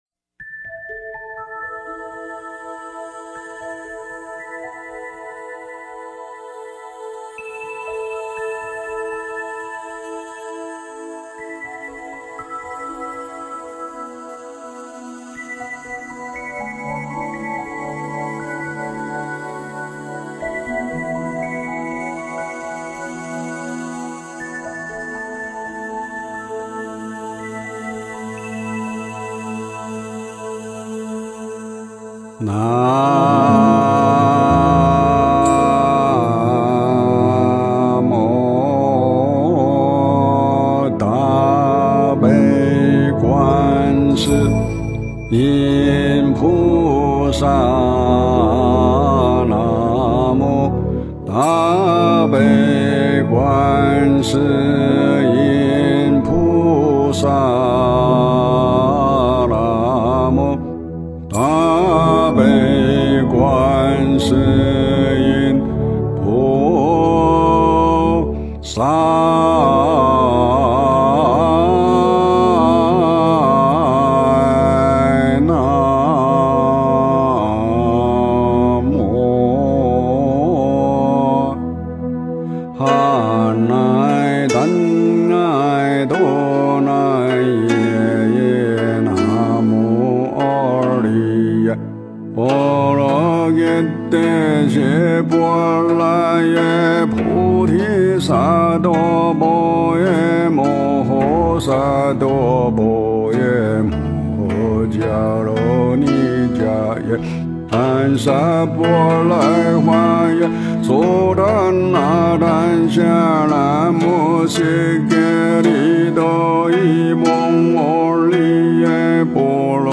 诵经
佛音 诵经 佛教音乐 返回列表 上一篇： 拜愿 下一篇： 观音圣号(闽南语合唱版